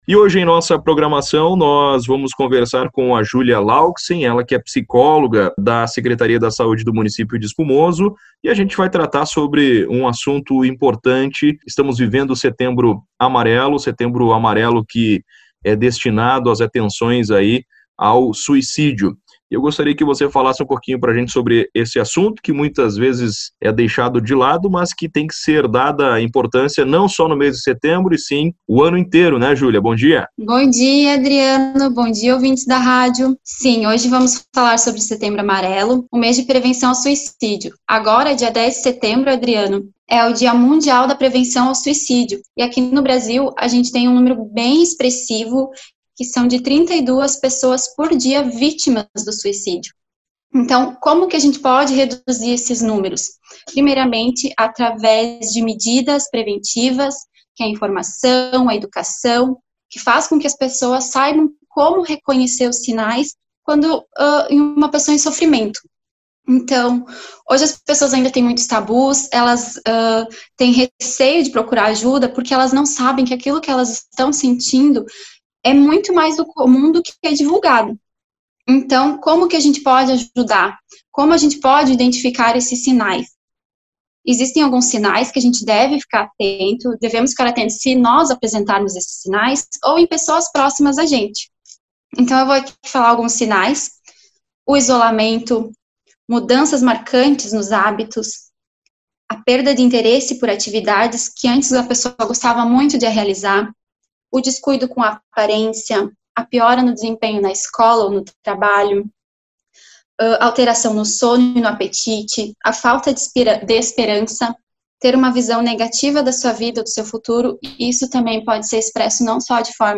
Psicóloga fala sobre o setembro amarelo, mês de combate ao suicídio